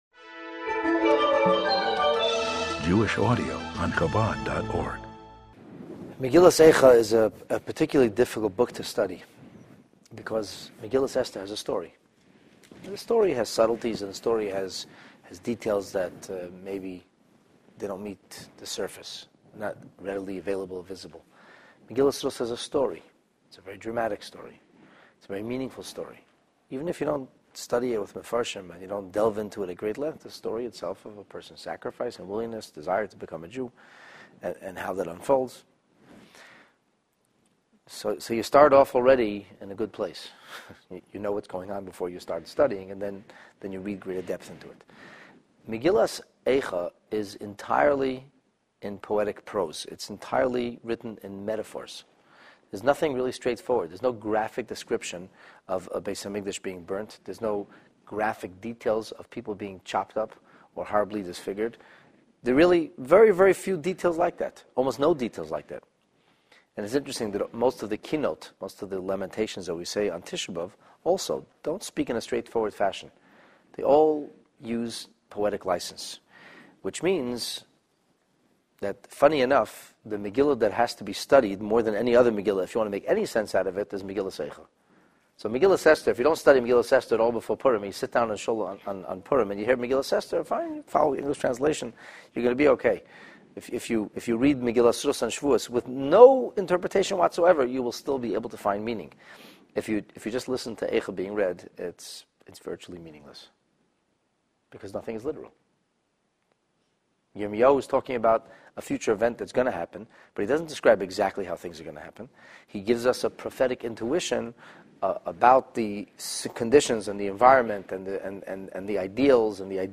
This text-based class on Megillat Eicha focuses on verse 6 of the first chapter.